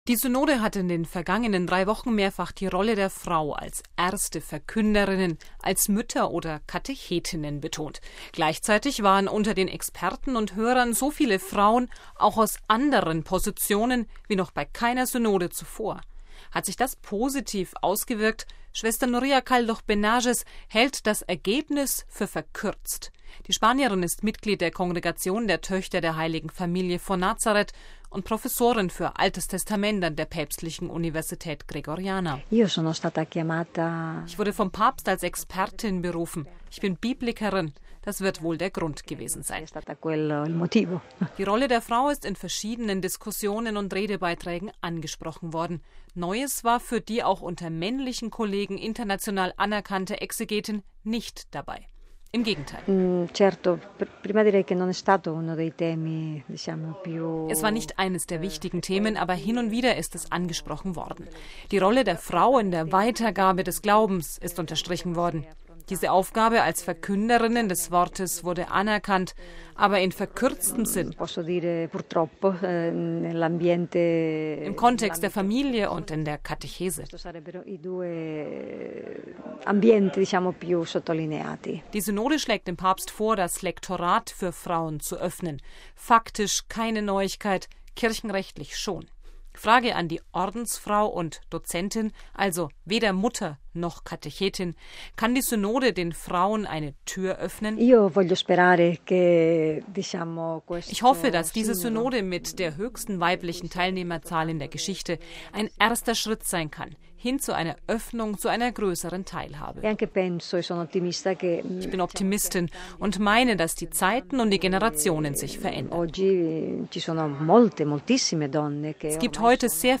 Frage an die Ordensfrau und Dozentin: Kann die Synode den Frauen eine Tür öffnen?